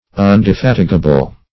Search Result for " undefatigable" : The Collaborative International Dictionary of English v.0.48: Undefatigable \Un`de*fat"i*ga*ble\, a. Indefatigable.